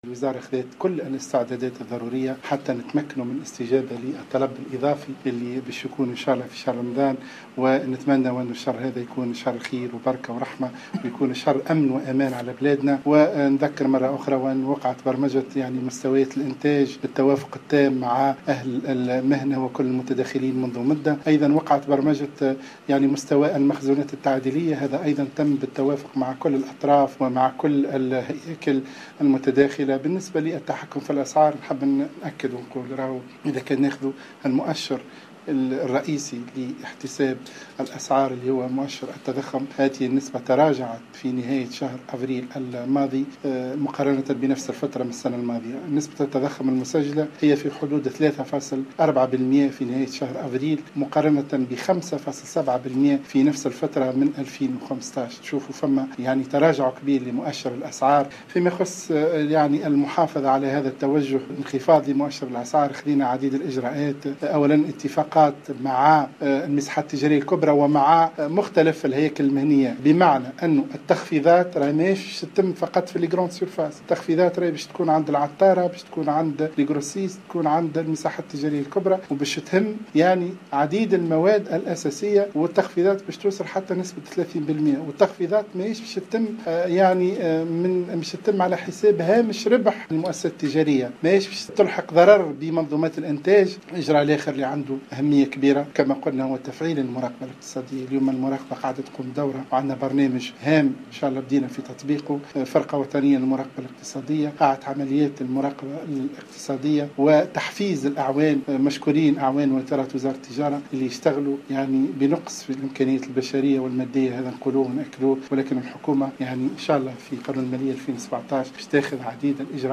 وأضاف الوزير خلال ندوة صحفية عقدها اليوم الخميس بمجلس نواب الشعب بحضور رئيس لجنة الفلاحة والتجارة حول آخر الاستعدادات لشهر رمضان أنه تم الاتفاق على إقرار تخفيضات مهمة في أسعار بعض المواد الاستهلاكية في الفضاءات التجارية الكبرى و بائعي الجملة و التفصيل تصل أحيانا إلى 30 بالمائة.